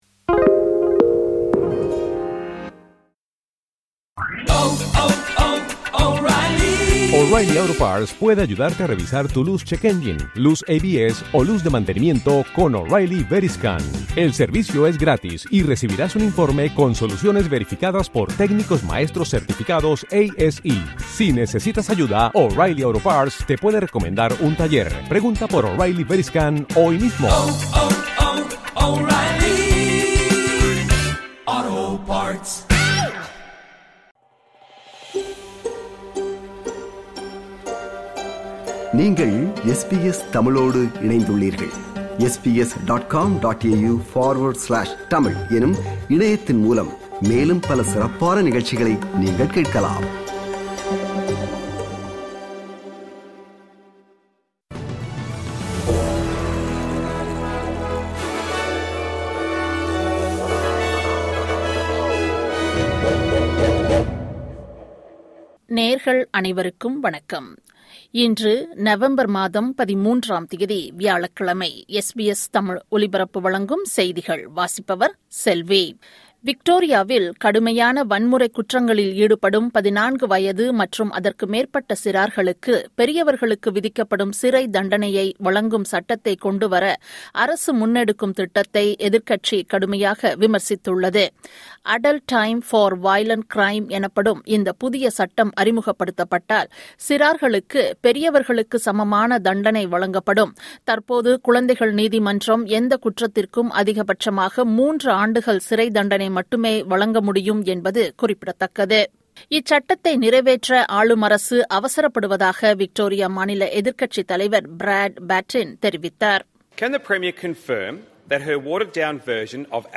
SBS தமிழ் ஒலிபரப்பின் இன்றைய (வியாழக்கிழமை 13/11/2025) செய்திகள்.